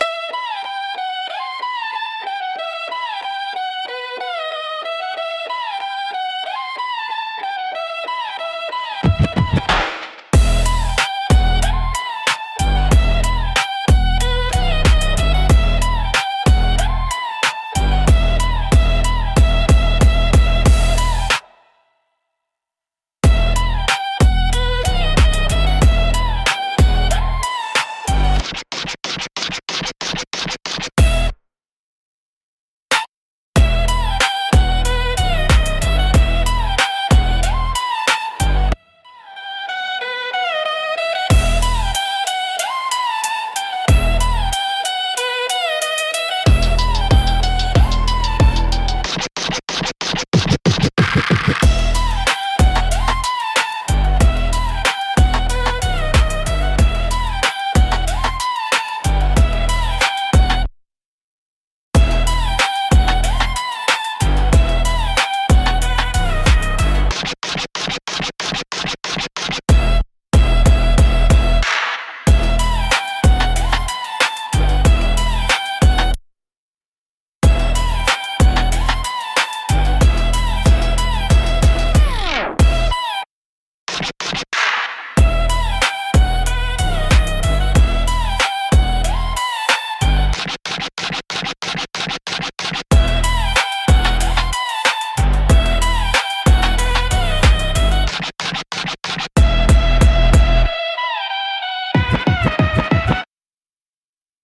Instrumentals - Echoes from the Cypher (1)